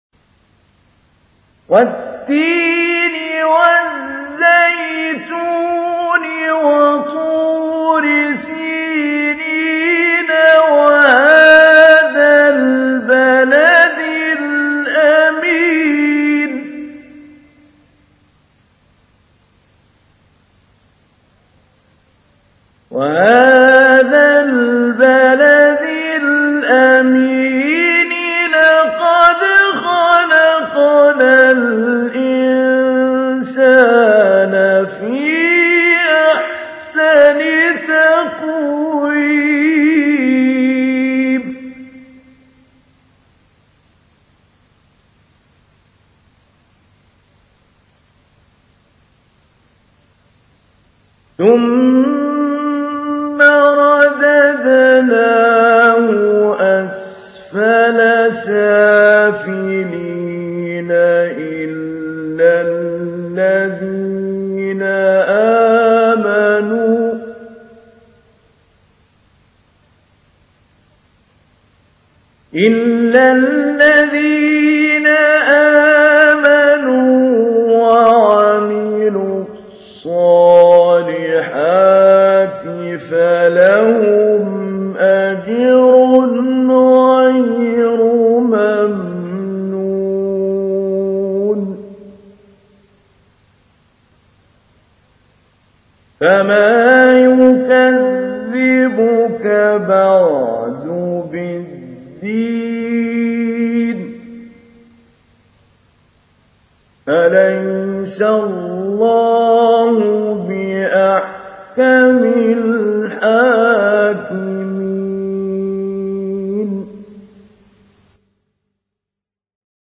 سورة التين | القارئ محمود علي البنا